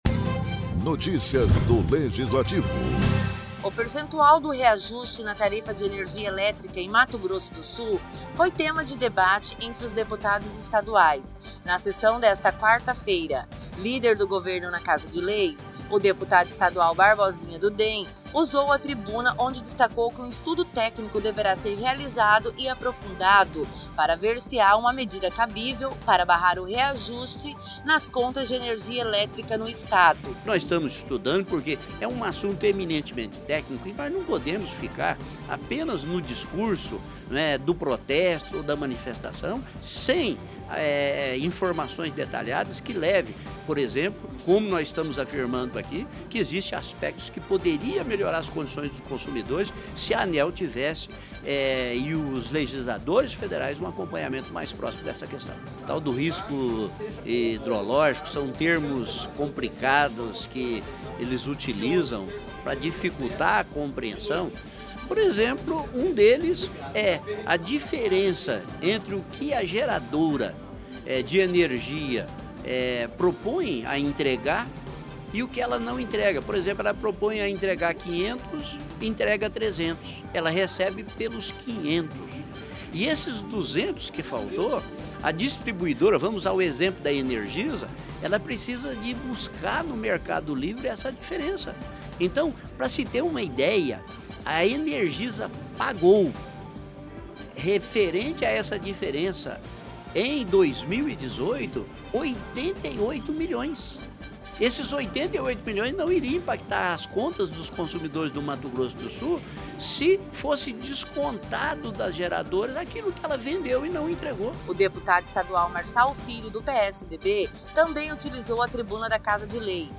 Durante a sessão plenária desta quarta-feira, os deputados estaduais debateram uma forma para barrar o reajuste nas contas de tarifa de energia elétrica em Mato Grosso do Sul.